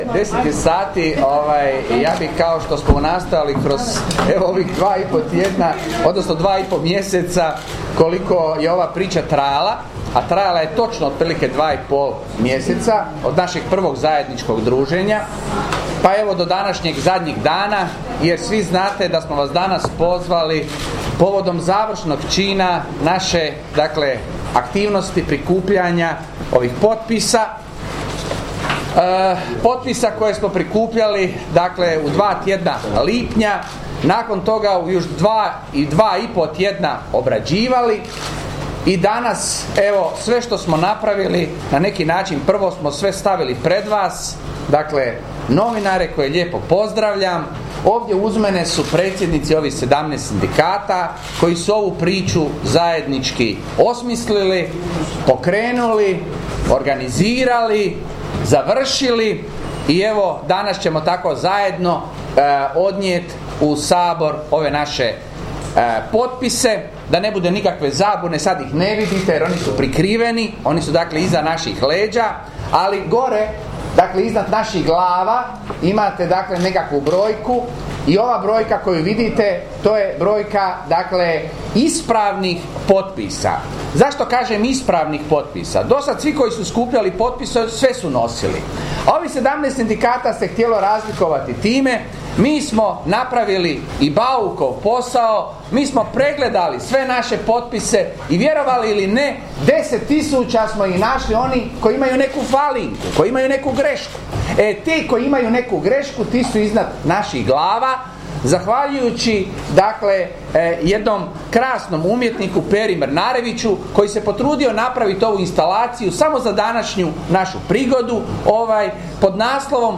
6.7.2014. - Konferencija za medije sindikata javnih i drzavnih službi
Poslušajte audio zapis tiskovne konferencije prije predaje potpisa: